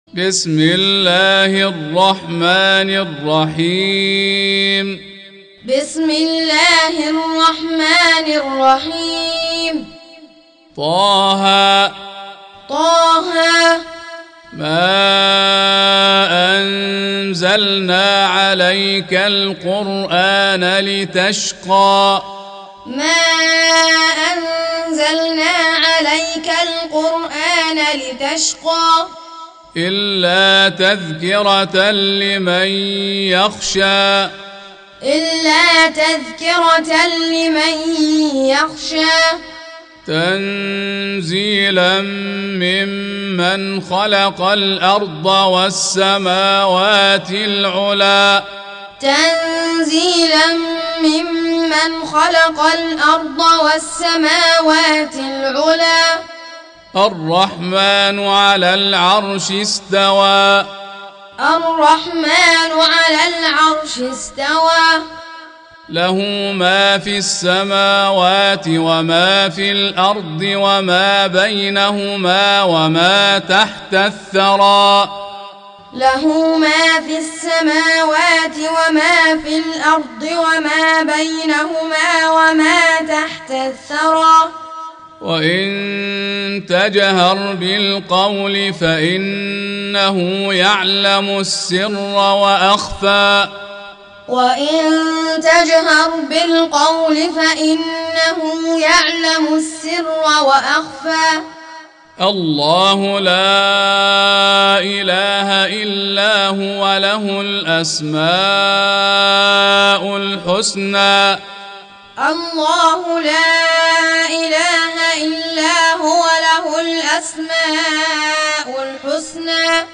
Surah Repeating تكرار السورة Download Surah حمّل السورة Reciting Muallamah Tutorial Audio for 20. Surah T�H�. سورة طه N.B *Surah Includes Al-Basmalah Reciters Sequents تتابع التلاوات Reciters Repeats تكرار التلاوات